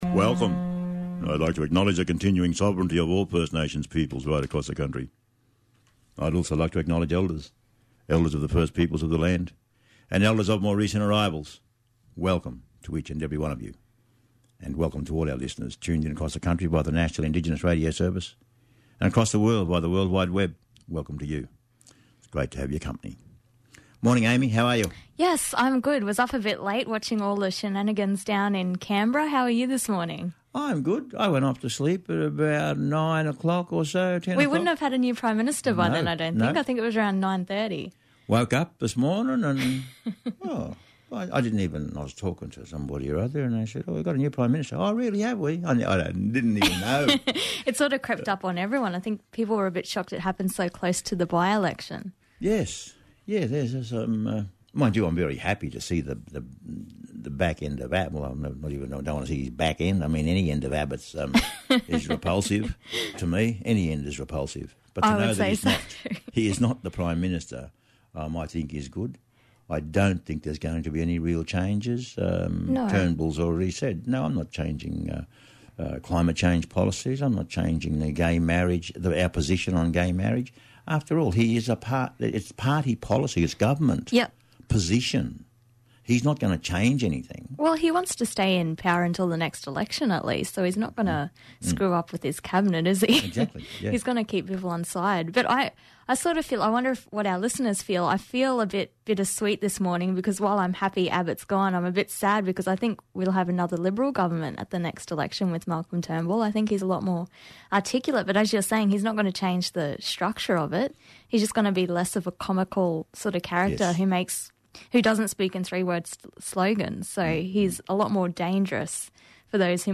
talk with June Oscar, CEO of Marninwarntikura Fitzroy Women’s Resource Centre.